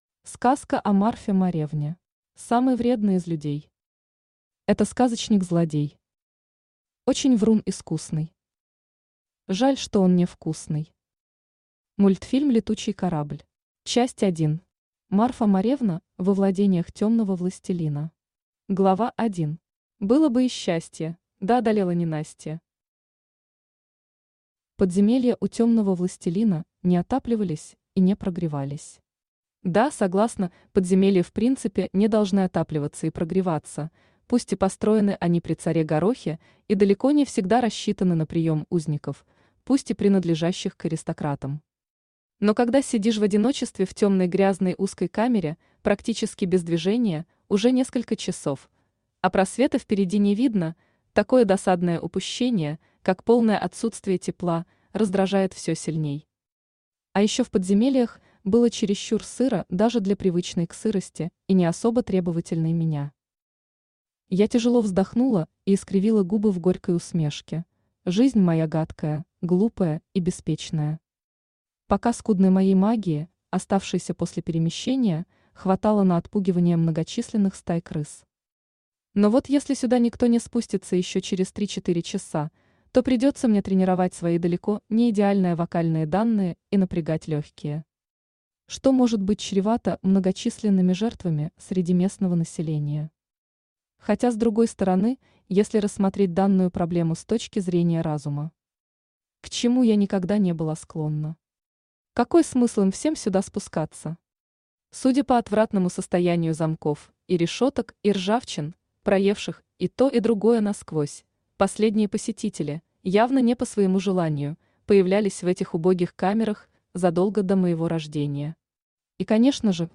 Аудиокнига Сказка о Марфе Моревне | Библиотека аудиокниг
Aудиокнига Сказка о Марфе Моревне Автор Надежда Игоревна Соколова Читает аудиокнигу Авточтец ЛитРес.